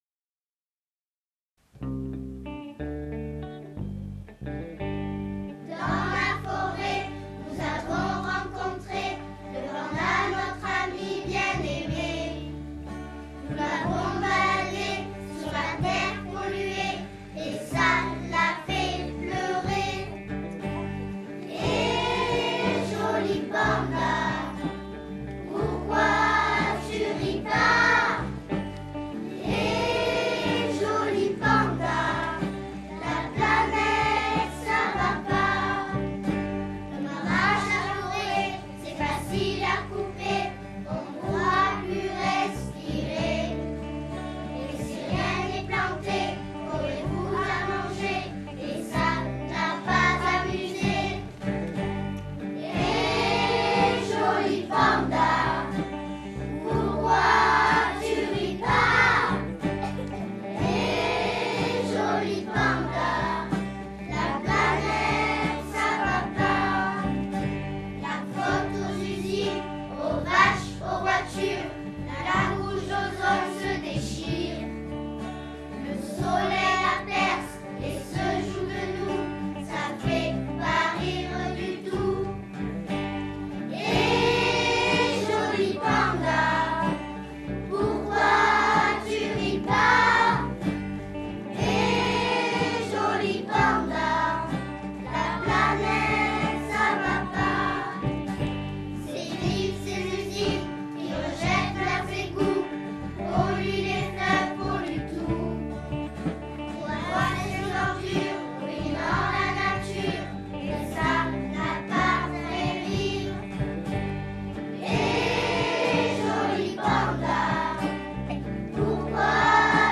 Chant : Hé le Panda! (version terre)